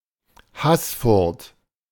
Haßfurt (German pronunciation: [ˈhasˌfʊʁt]
De-Haßfurt.ogg.mp3